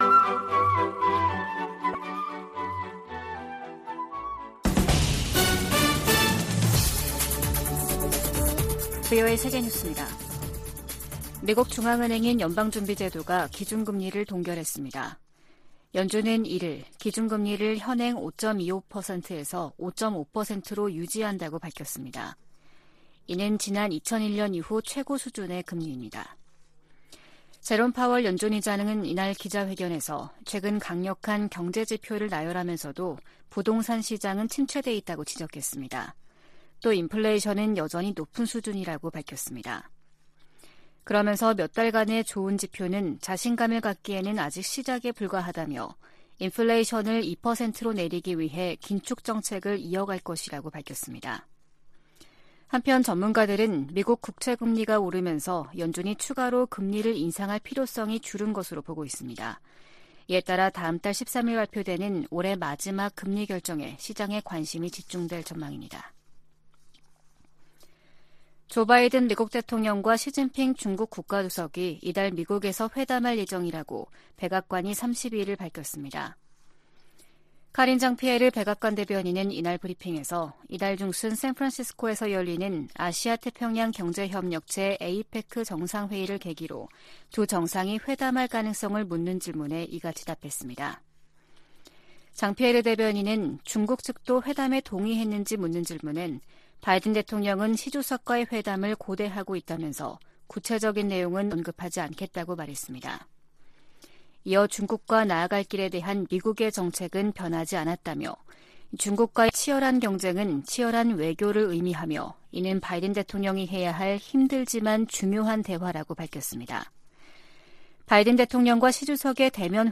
VOA 한국어 아침 뉴스 프로그램 '워싱턴 뉴스 광장' 2023년 11월 2일 방송입니다. 미 국방부는 한국 정부가 9.19 남북군사합의의 효력 정지를 검토 중이라고 밝힌 데 대해 북한 위협에 대응해 한국과 계속 협력할 것이라고 밝혔습니다. 한국 국가정보원은 북한이 러시아의 기술자문을 받으면서 3차 군사정찰위성 발사 막바지 준비를 하고 있다고 밝혔습니다. 미 국토안보부장관은 북한 등 적성국 위협이 진화하고 있다고 말했습니다.